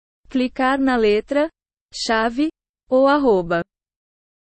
clicar-chave-ou-arroba.wav